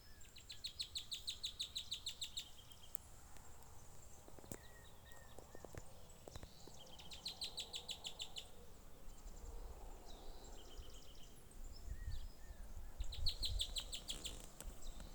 Bandurrita Chaqueña (Tarphonomus certhioides)
Nombre en inglés: Chaco Earthcreeper
Localidad o área protegida: Amaicha del Valle
Condición: Silvestre
Certeza: Observada, Vocalización Grabada
bandurrita-chaquena1-mp3.mp3